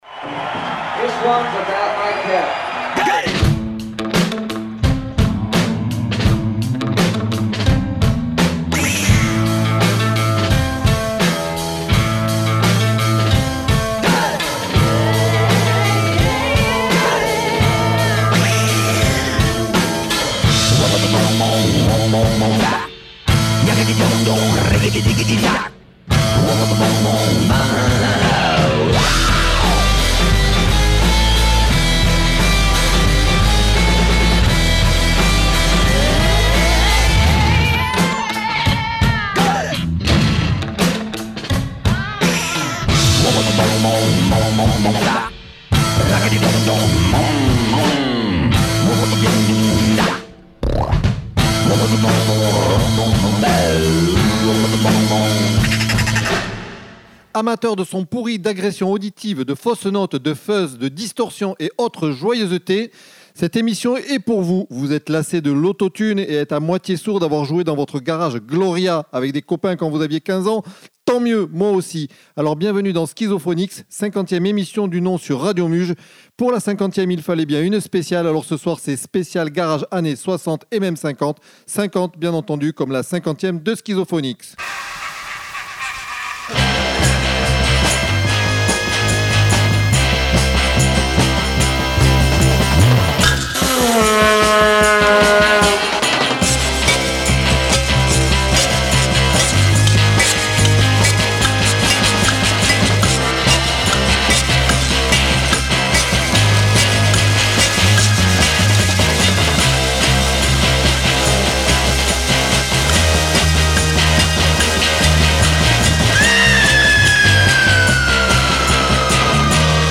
pour une spéciale rock 50's / 60's.